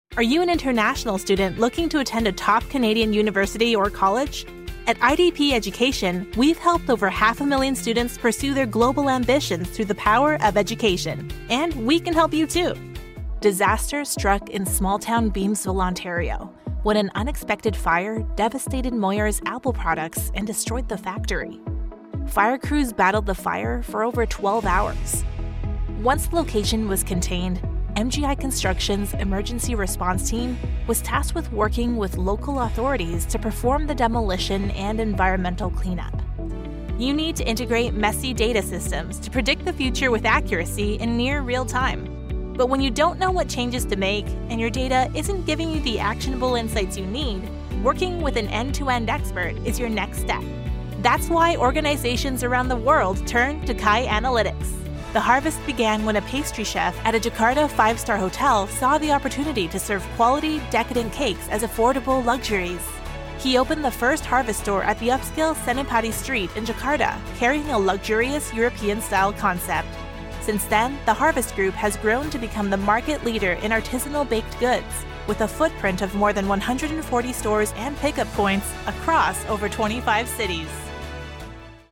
Englisch (Amerikanisch)
Natürlich, Zuverlässig, Freundlich
Unternehmensvideo